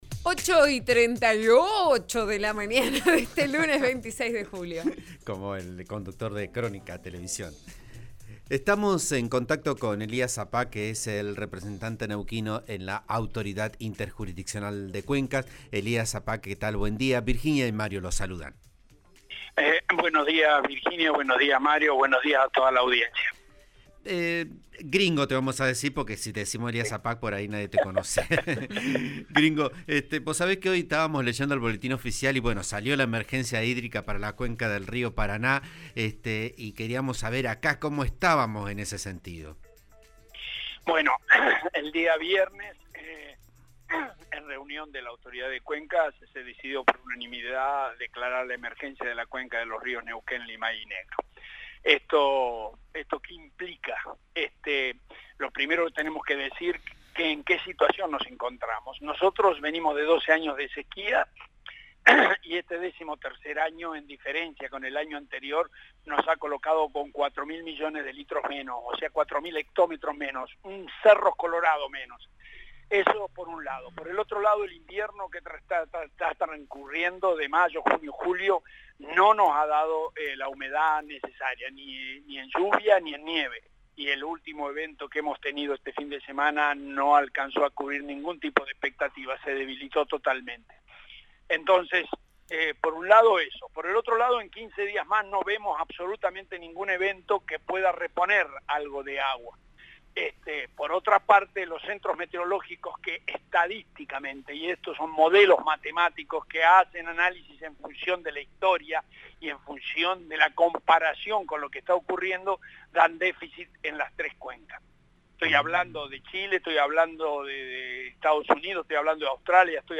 Así lo señaló, Elias «Gringo» Sapag, representante de Neuquén en la AIC, en declaraciones al programa Vos a Diario, de RN RADIO (89.3).